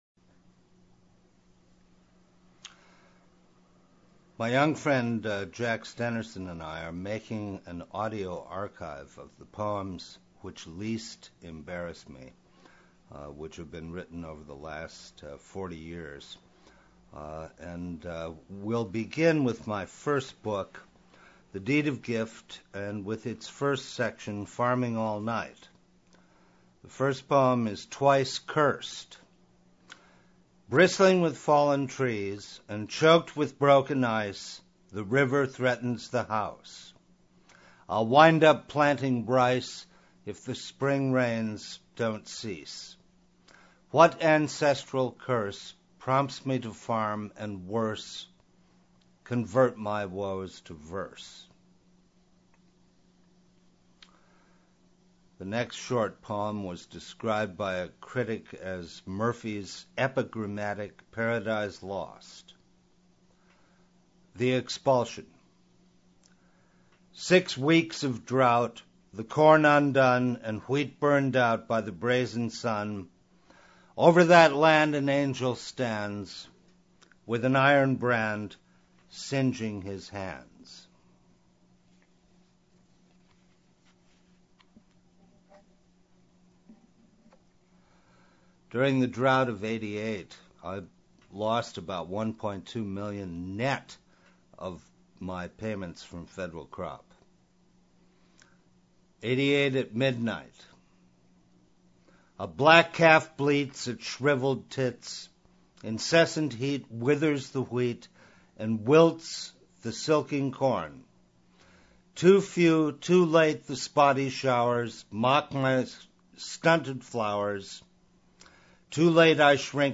It's in four parts, but I've combined them into one continuous audio file. It comprises all six of his books of poetry.
This is a collection of some of the finest writing out there, and professionally recorded.